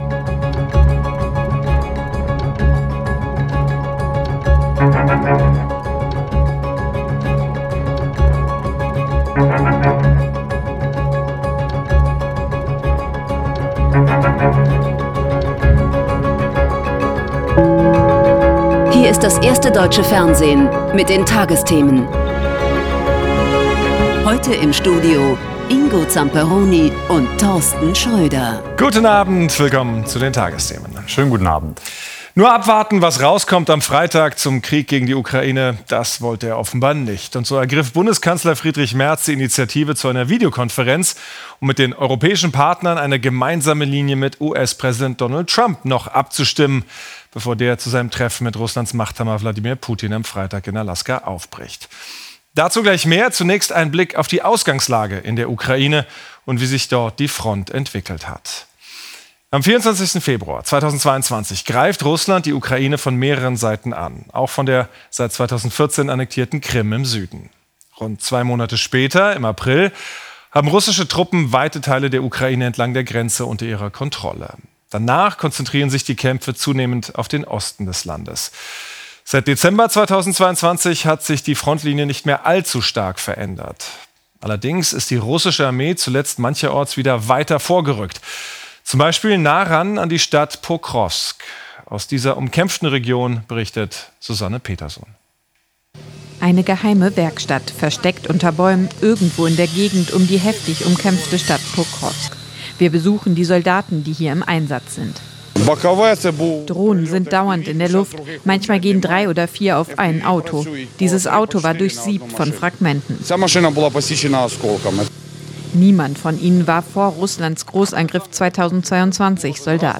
Die tagesthemen als Audio-Podcast.